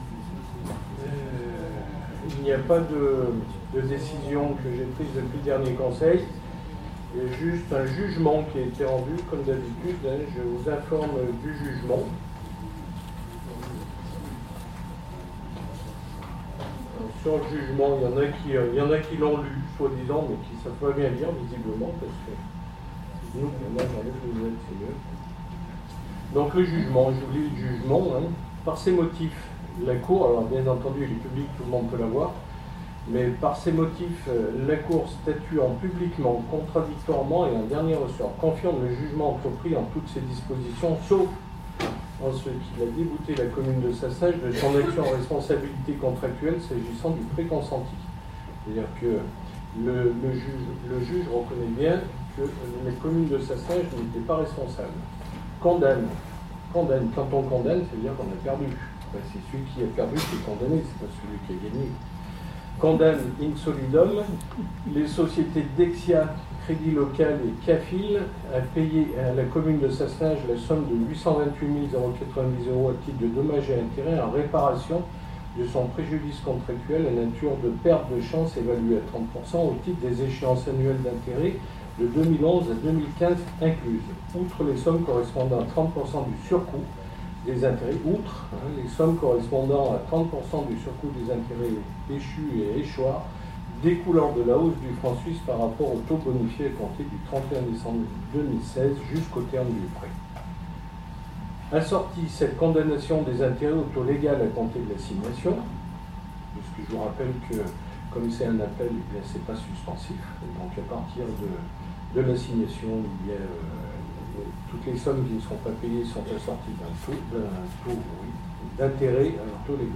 En début de séance, C. Coigné a lu l’arrêt de la Cour, en donnant sa propre interprétation de cette décision.
Florence Parvy, élue du groupe APS a essayé, en vain, d’intervenir après la présentation de C. Coigné. Elle s’est fait interrompre par le Maire, son micro a été coupé et malgré ses tentatives de parler elle n’a pu présenter son intervention.